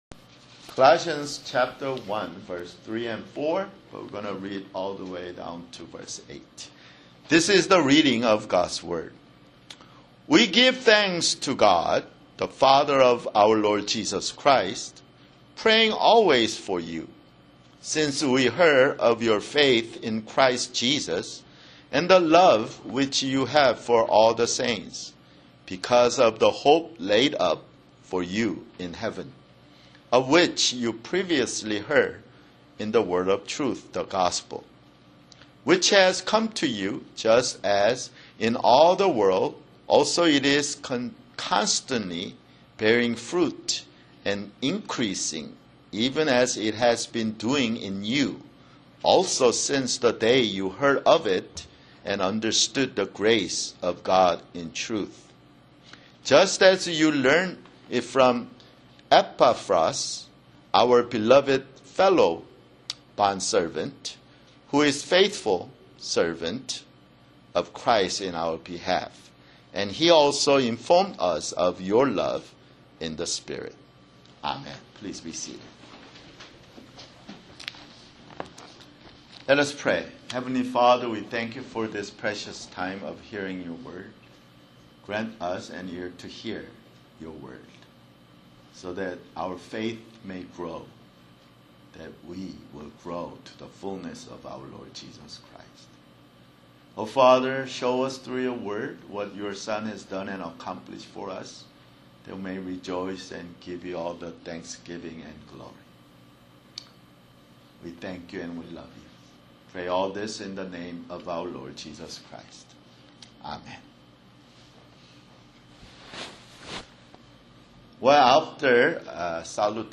Sunday, November 5, 2017 [Sermon] Colossians (9) Colossians 1:3-8 (9)